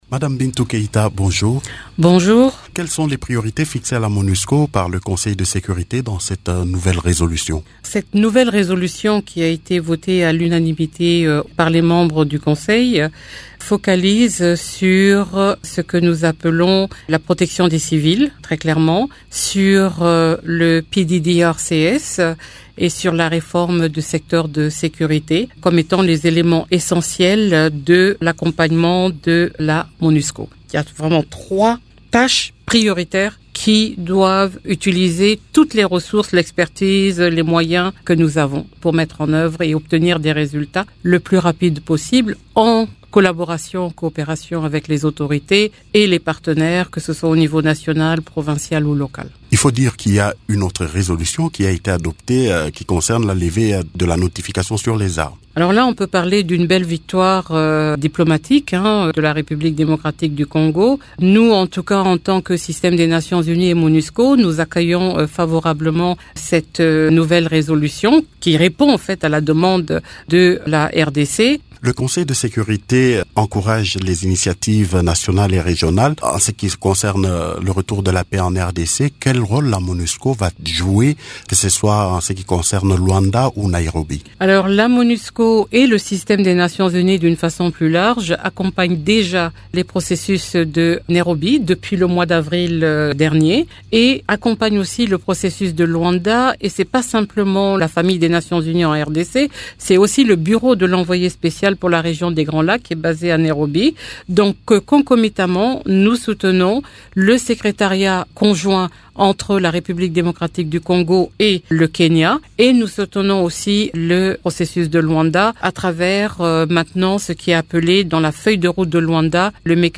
Invitée de Radio Okapi, la cheffe de la MONUSCO, elle indique qu’avec la nouvelle résolution, la MONUSCO a trois prioritaires à savoir : la protection des civils, le PDDRCS et la reforme de secteur de sécurité de la RDC.